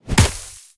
File:Sfx creature pinnacarid hop fast 03.ogg - Subnautica Wiki
Sfx_creature_pinnacarid_hop_fast_03.ogg